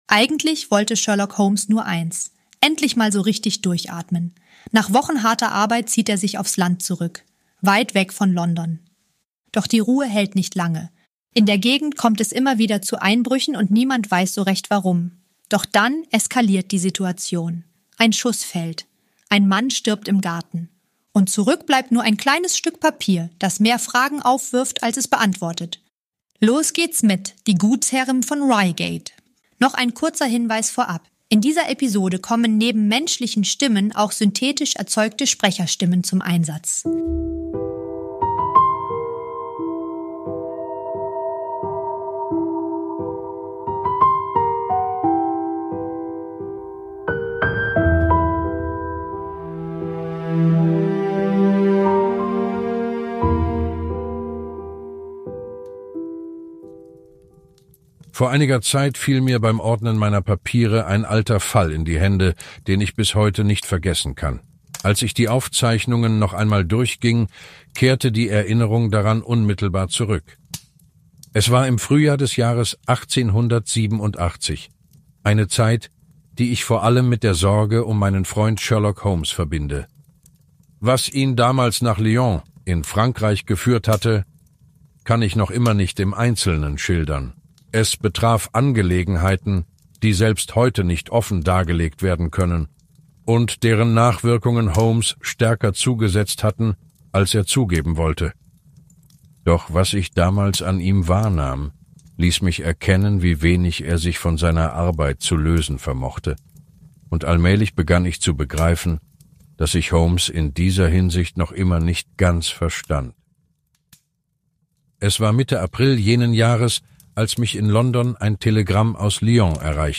- In dieser Produktion kommen neben unseren eigenen Stimmen auch synthetische Sprecherstimmen zum Einsatz.